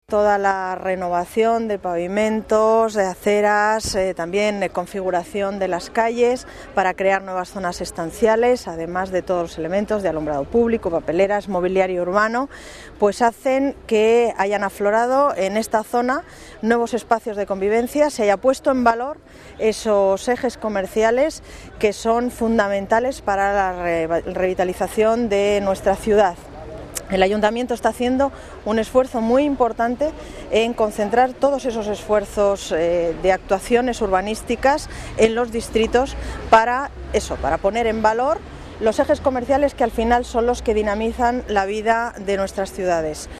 Nueva ventana:Declaraciones de la concejala de Medio Ambiente, Paz González, sobre la remodelación del eje comercial de Moratalaz